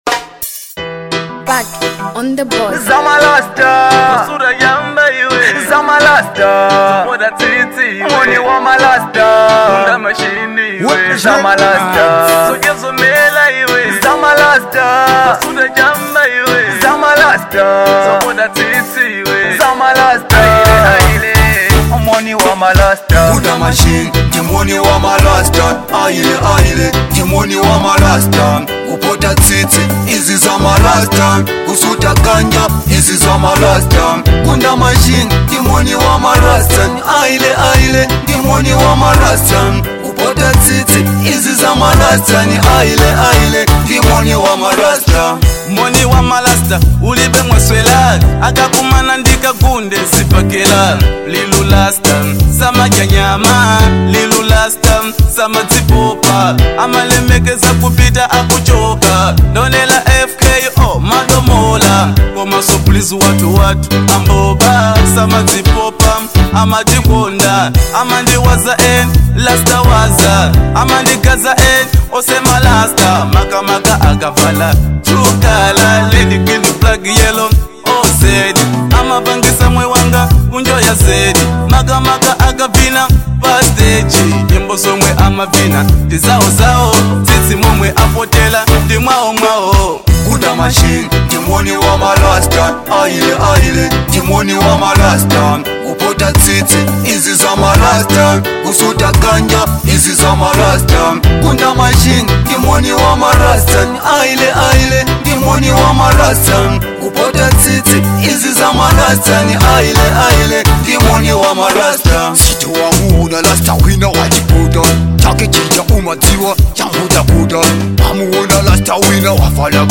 Genre : Reggie Dancehall